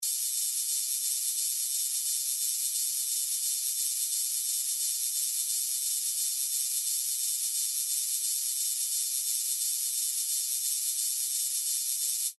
На этой странице собраны разнообразные звуки лазеров — от тонких высокочастотных писков до мощных энергетических залпов.
Звук лазерной коррекции зрения